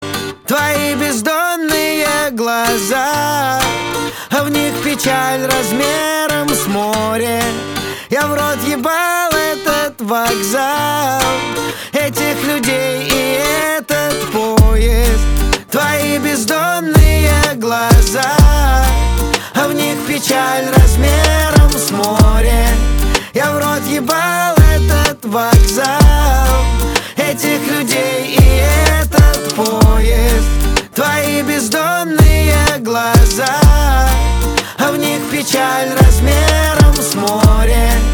• Качество: 320, Stereo
гитара
мужской голос
грустные
русский рэп
спокойные